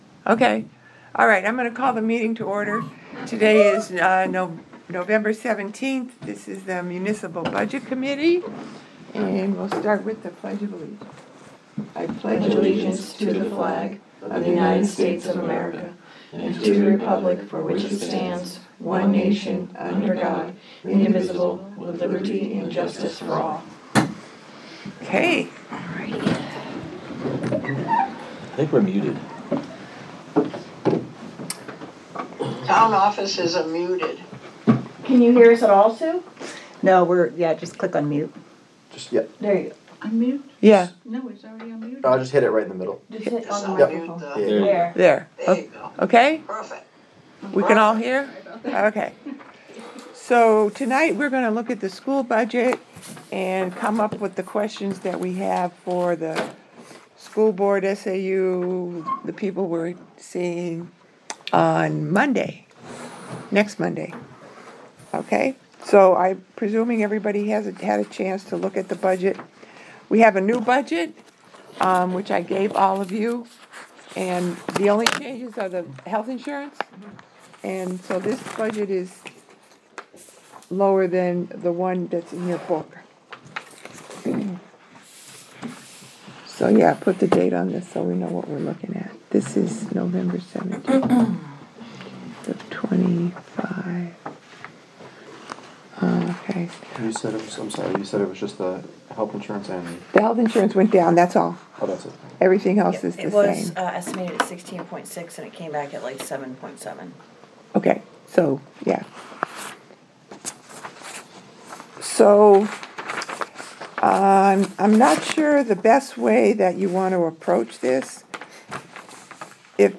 Audio recordings of committee and board meetings.
Budget Committee Meeting